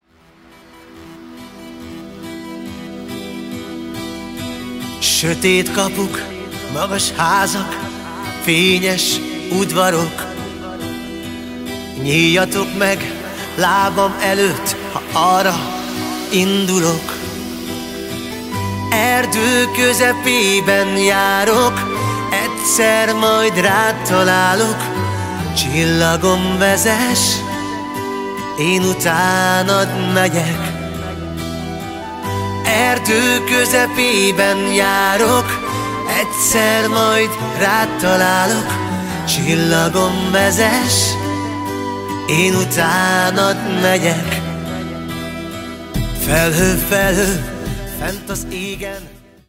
Minőség: 320 kbps 44.1 kHz Stereo